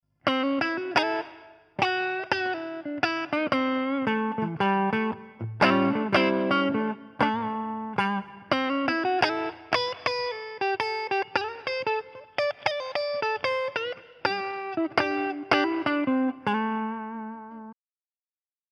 Alle Soundbeispiele wurden mit einer Harley Benton Fusion T eingespielt und mit einem Shure SM57 abgenommen. Dazu kommt ein wenig Hall von einem Keeley Caverns Pedal.
1Watt,Volume 4, Tone 2, Neck Humbucker Lead